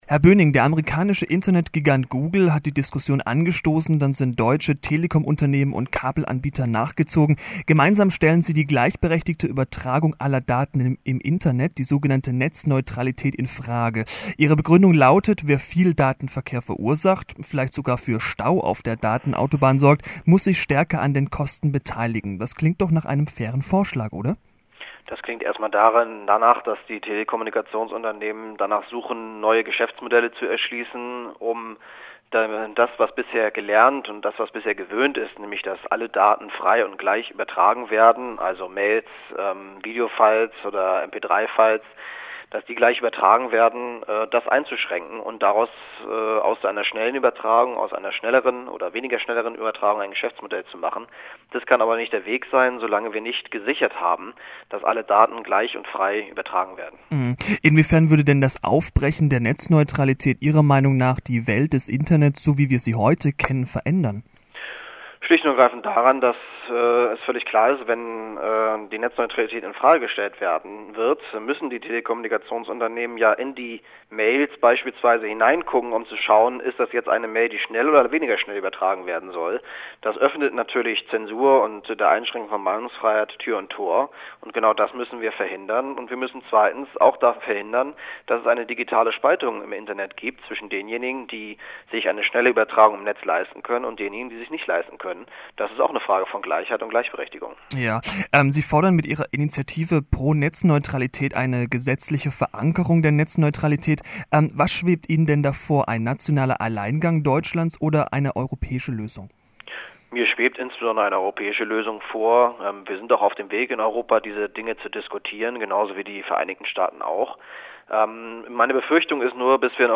Zweiklassensystem im Internet? ? ein Interview mit dem Mitinitiator der Kampagne ?Pro-Netzneutralität? und SPD-Politiker Björn Böhning